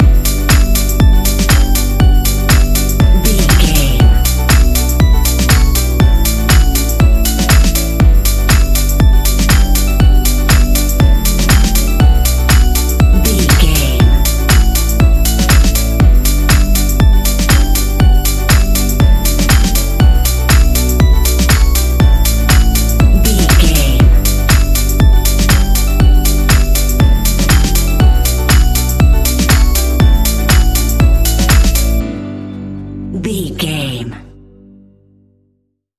Ionian/Major
E♭
house
electro dance
synths
techno
trance
instrumentals